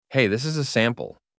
Human-sounding.
PLAY DRING AI SAMPLE
Hire AI Co-Workers that speak like humans, perform as pros and scale on demand.